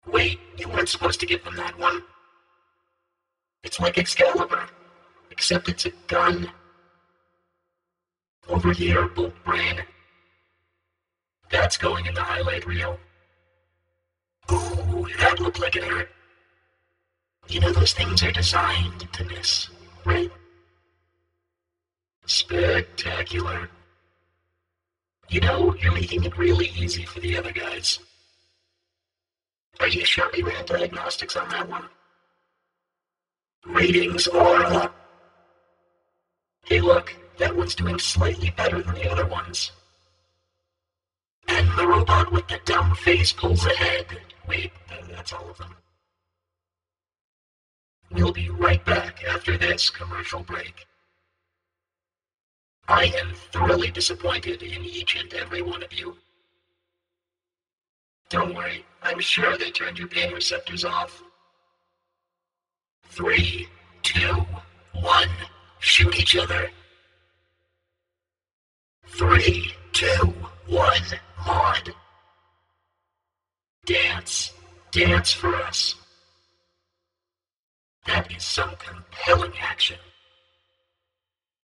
Logic’s vocoder has a lot of presets that will make vocal tracks sound robotic.
I needed the lines to sound as robotic as possible, while still being able to understand what the announcer was saying. So as a first pass for these lines, I ended up with this…
aud_vo_announcerlines03.mp3